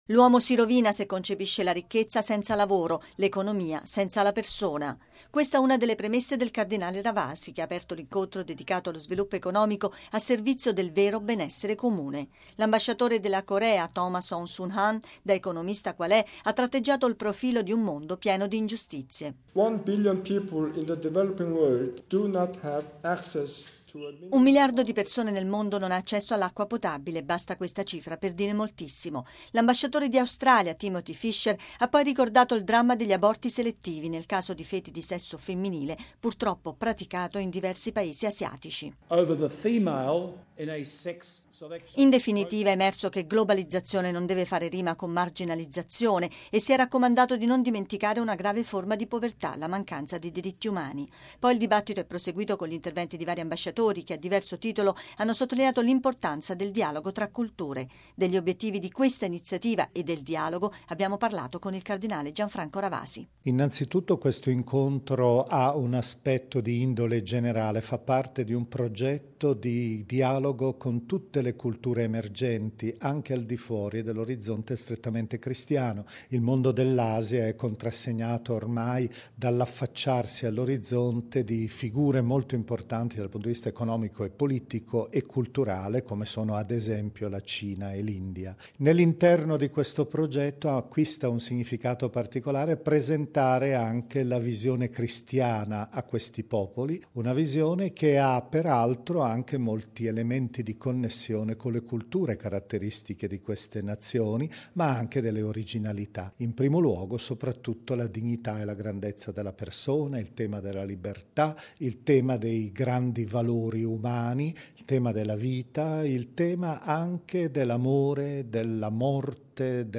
Degli obiettivi di questa iniziativa e del dialogo abbiamo parlato con il cardinale Gianfranco Ravasi: